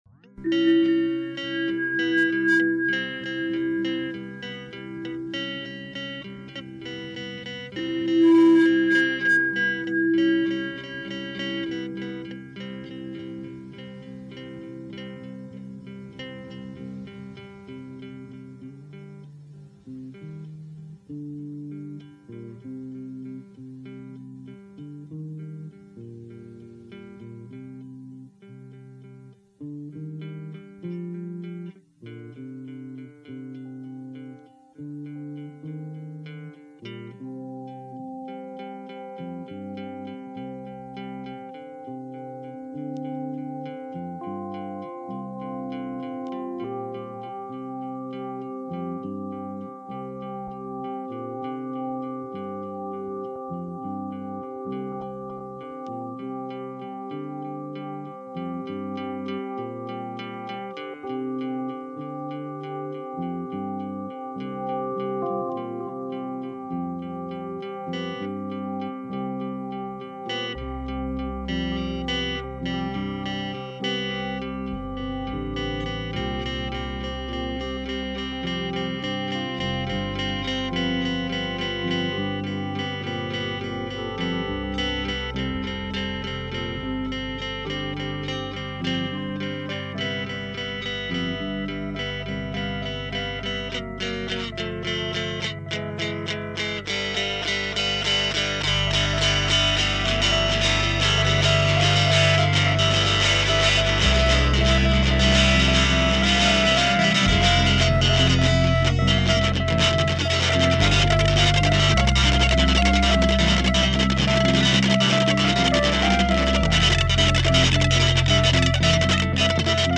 het resultaat van pure improvisatie